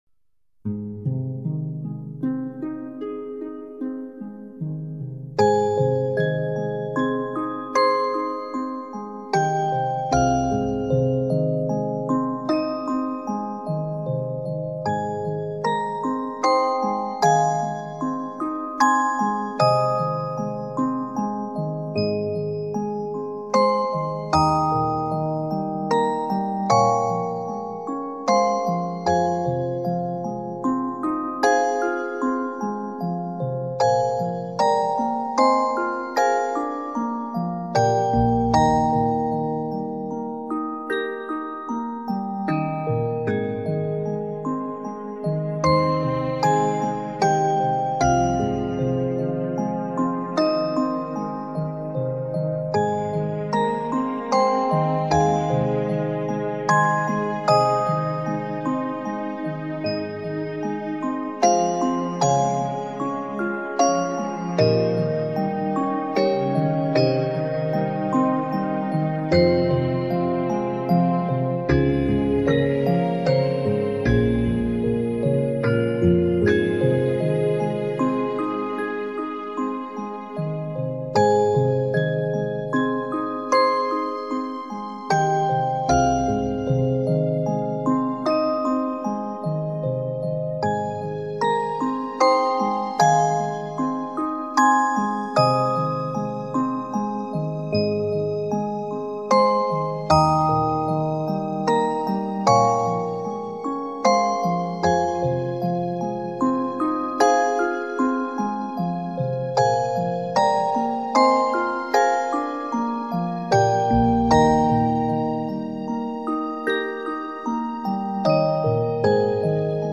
• Качество: Хорошее
🎶 Детские песни / Музыка детям 🎵 / Музыка для новорожденных